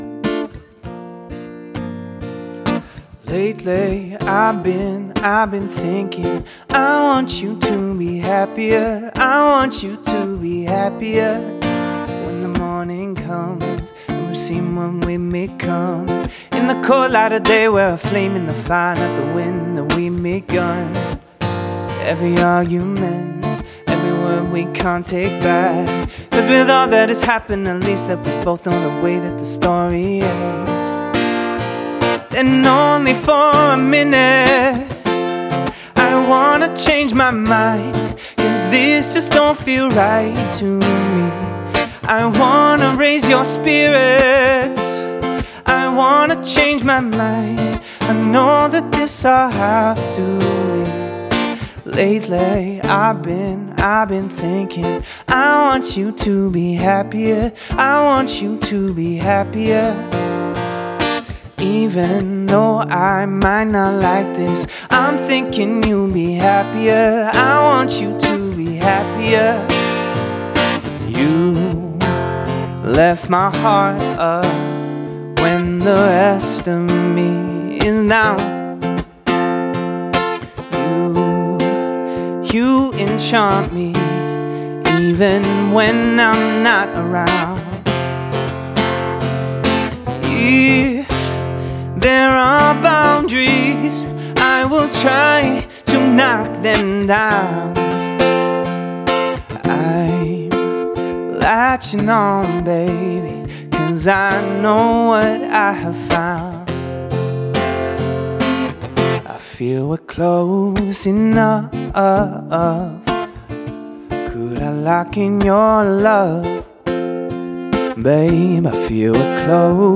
Vocals | Guitar |Looping, | DJ